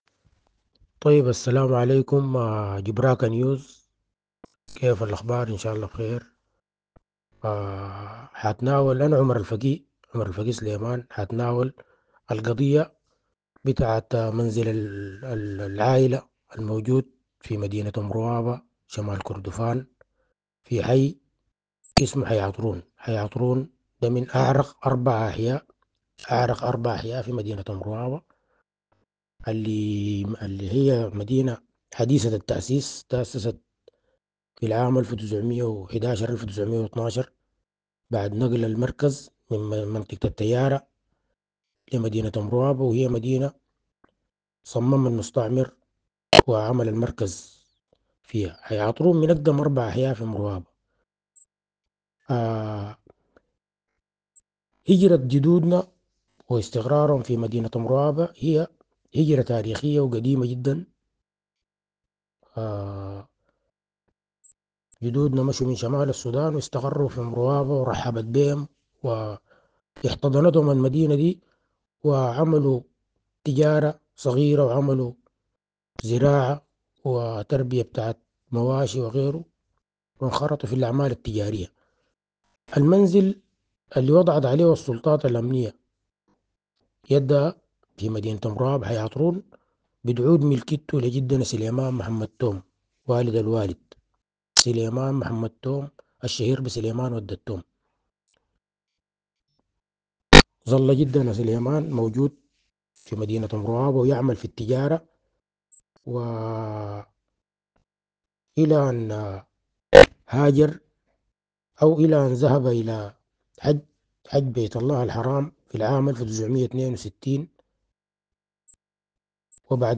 وفي مقابلة مع «جُبراكة نيوز»